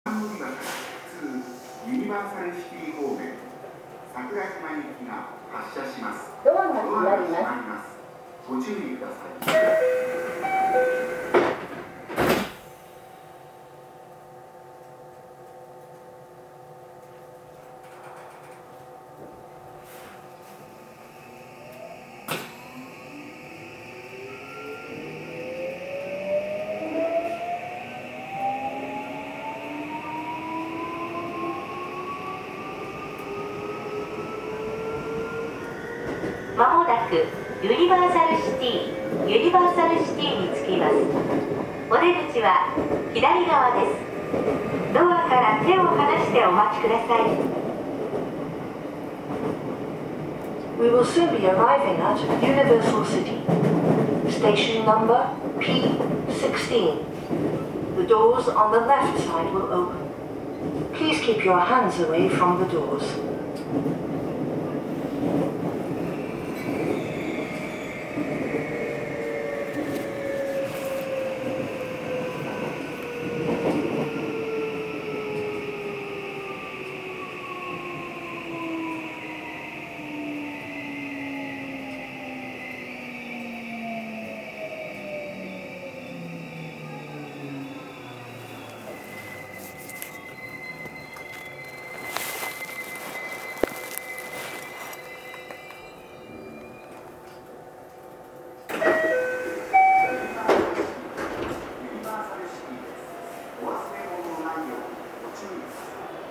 制御装置はSiC適用のMOSFET素子を使用した2レベル電圧形PWMインバータとなっており、消費電力削減を実現しています。
走行音
録音区間：安治川口～ユニバーサルシティ(お持ち帰り)